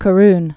(kə-rn, kä-)